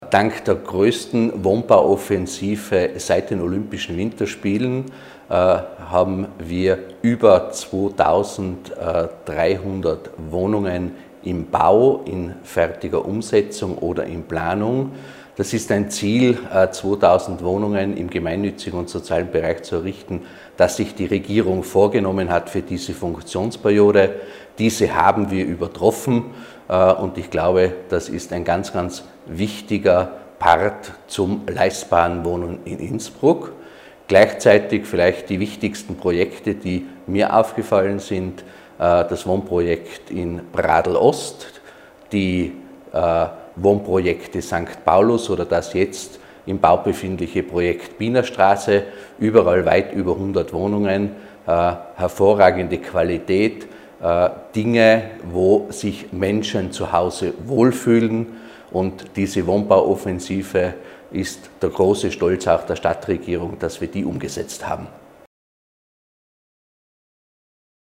OT von Stadtrat Andreas Wanker